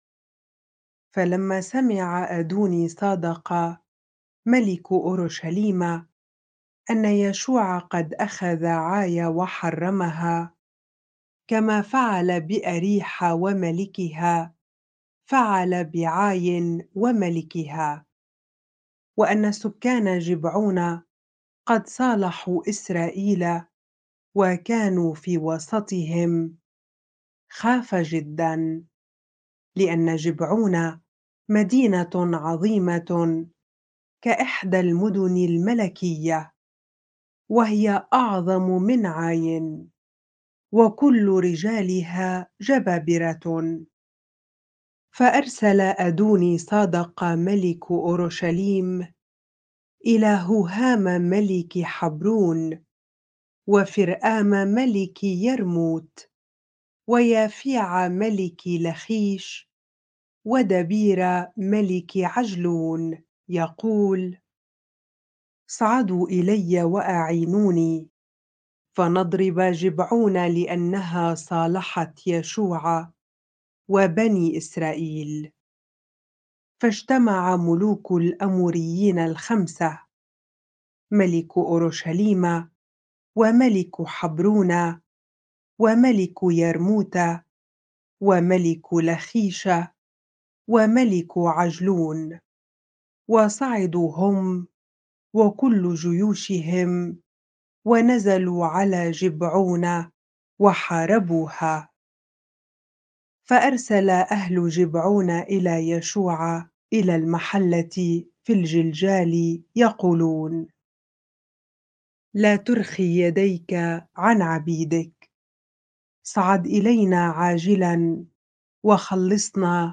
bible-reading-joshua 10 ar